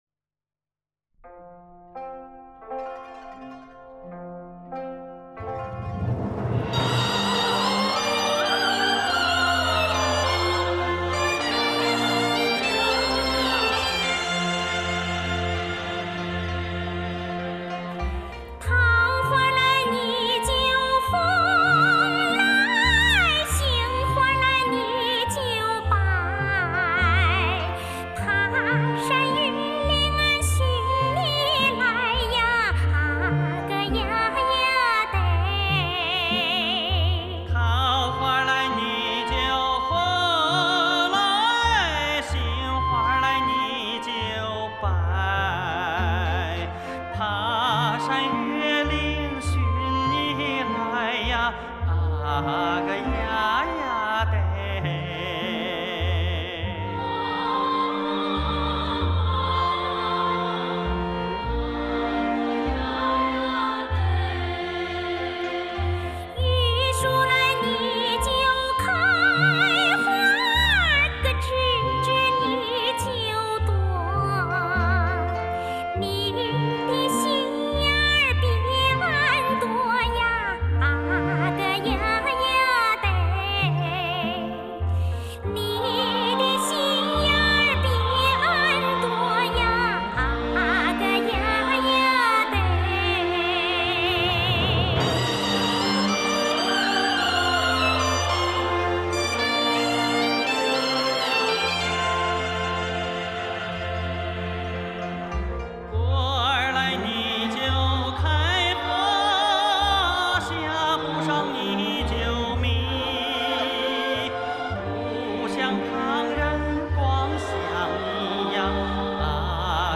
山西省内著名歌手演唱